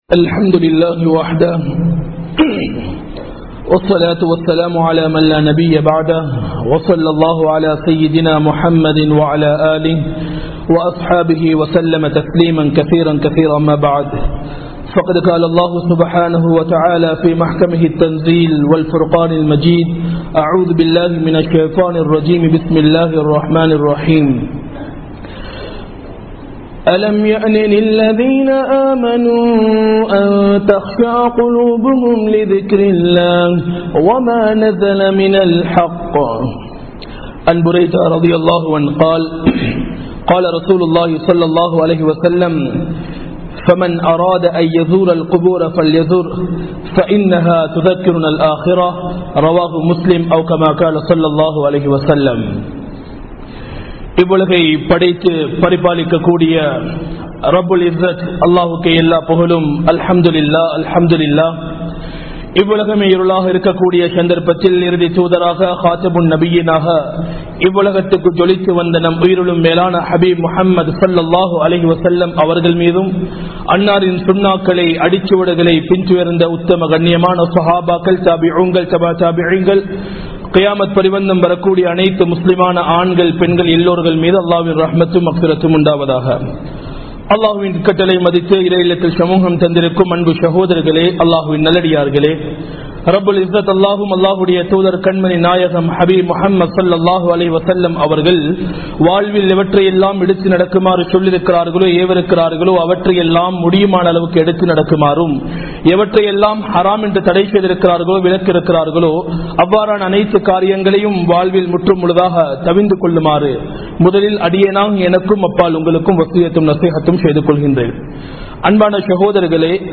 Marumaiyai Marantha Manitharhal (மறுமையை மறந்த மனிதர்கள்) | Audio Bayans | All Ceylon Muslim Youth Community | Addalaichenai
Dehiwela, Muhideen (Markaz) Jumua Masjith